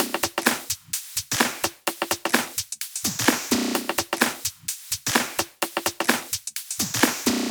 VFH3 128BPM Resistance Kit 2.wav